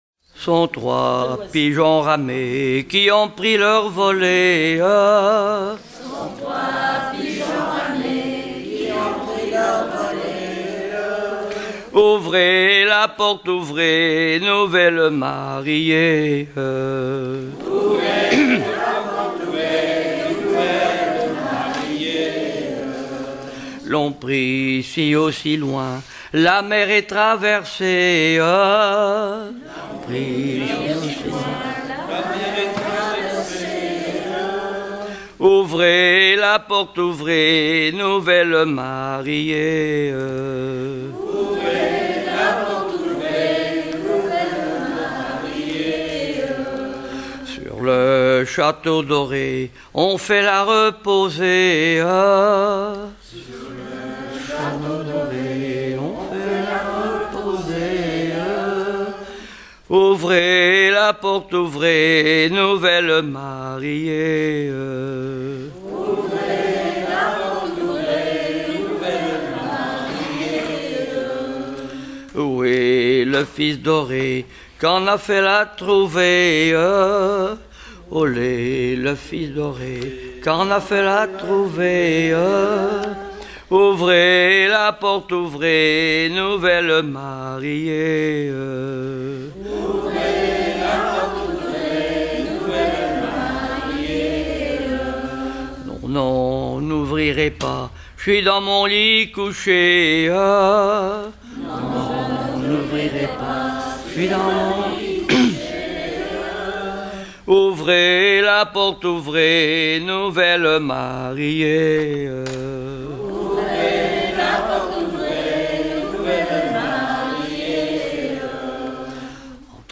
circonstance : fiançaille, noce
Genre strophique
Pièce musicale éditée